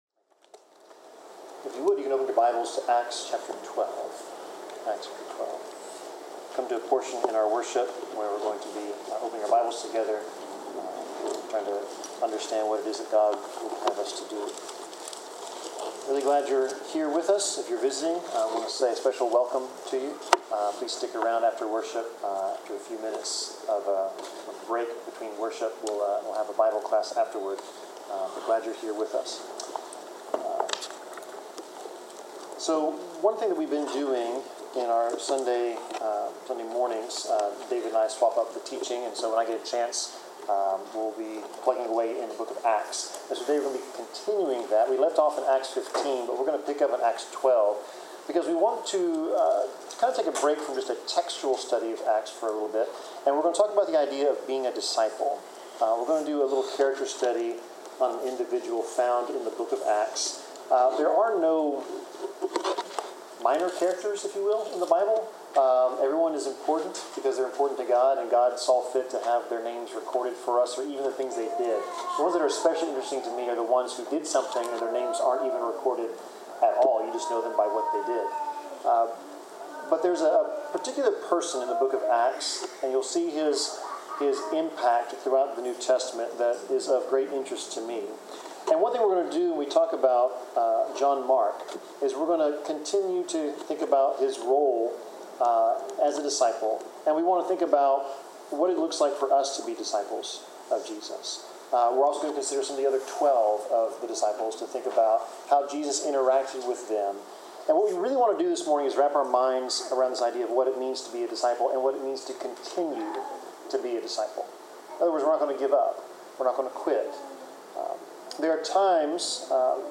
Service Type: Sermon Topics: Discipleship , Faith , Good Works , Holiness , Jesus , Love , Obedience , Promises of God , Relationship with God , Servitude « Bible class